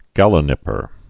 (gălə-nĭpər)